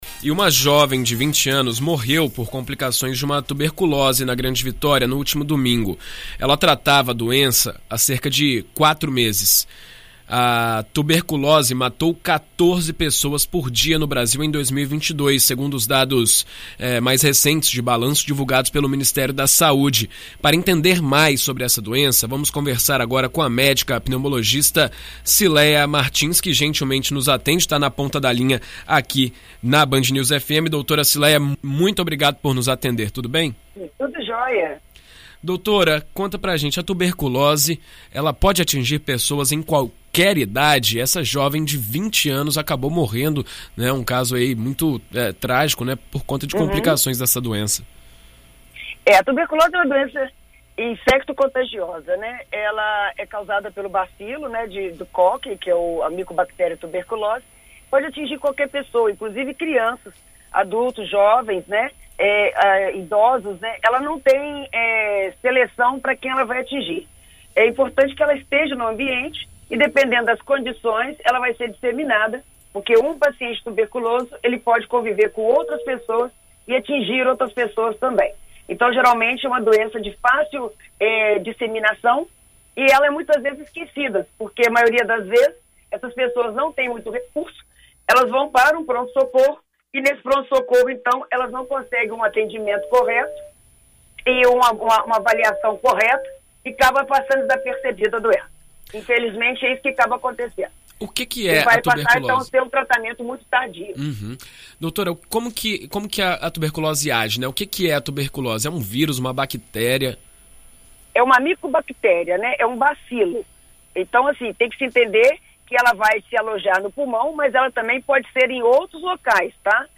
Em entrevista à BandNews FM ES nesta terça-feira